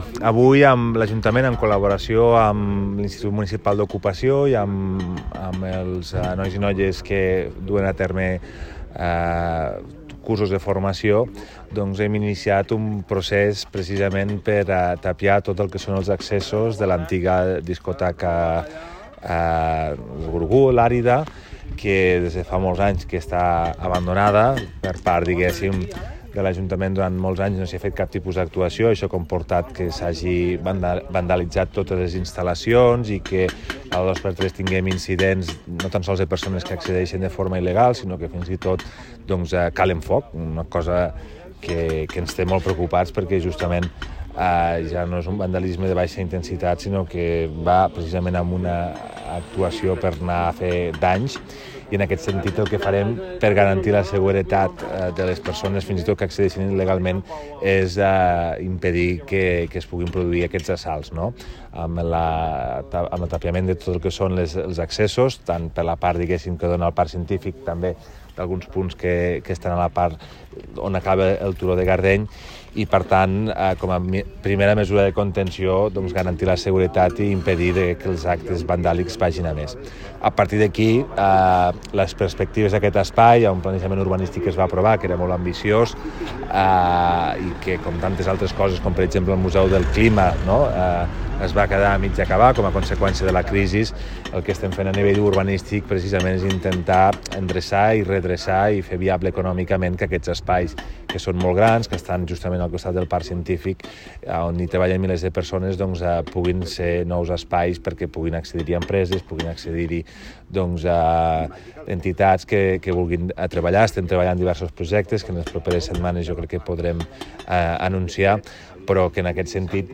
tall-de-veu-del-primer-tinent-dalcalde-toni-postius